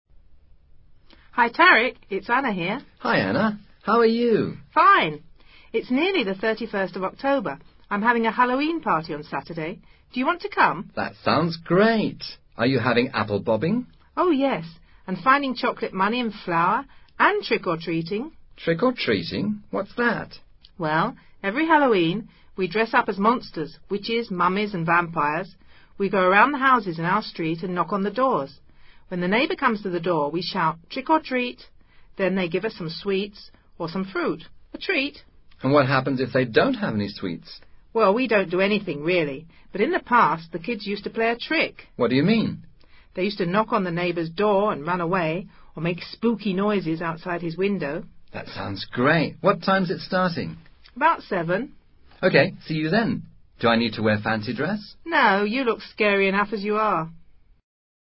Conversación entre dos personajes acerca de Halloween.